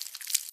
Sound / Minecraft / mob / silverfish / step3.ogg
step3.ogg